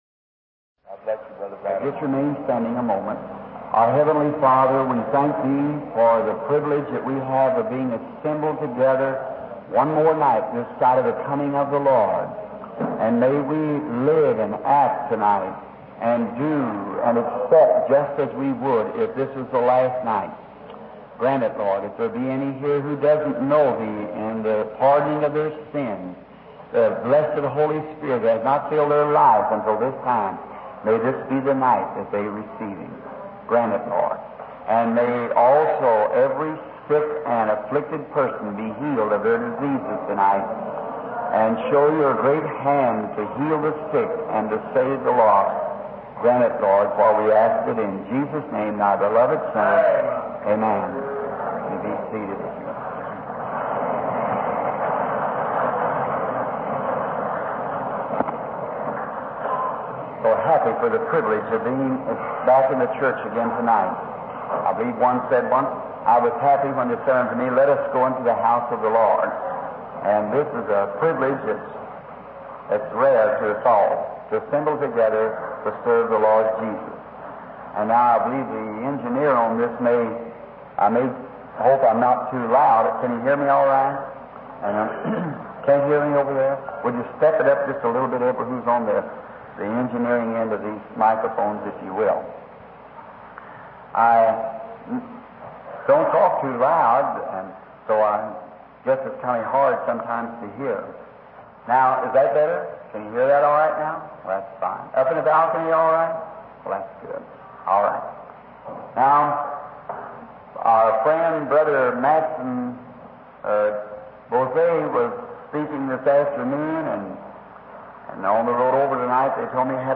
Predigten